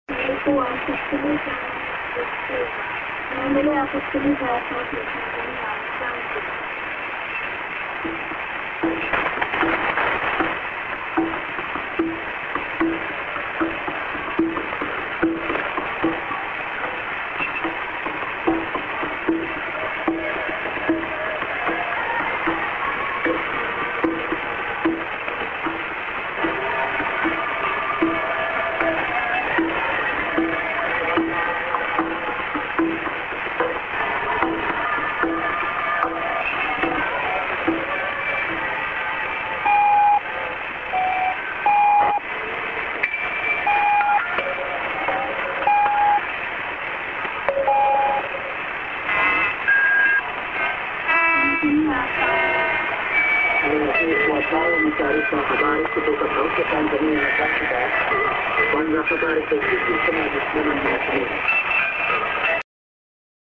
Tanzania Zanzibar(women)->durm->00'40":TS->ID:…Tanzania Zanziba…(man)